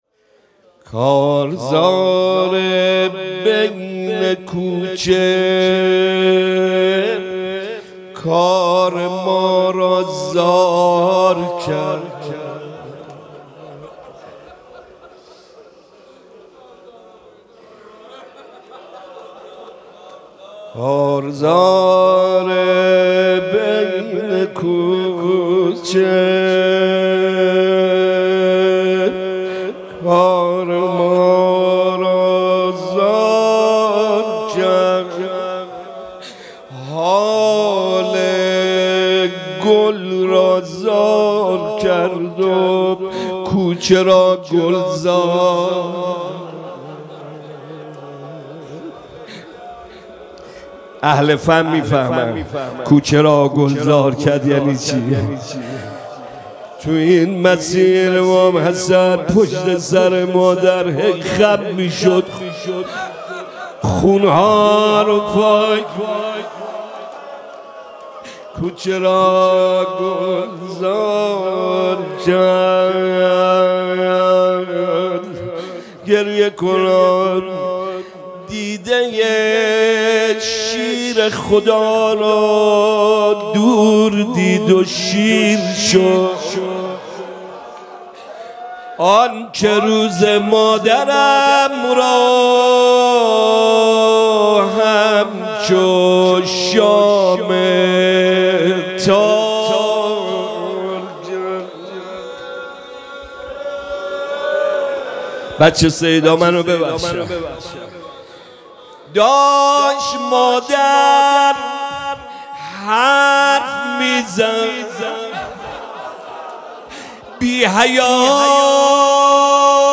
روضه حضرت زهرا سلام‌الله‌علیها – محتوانشر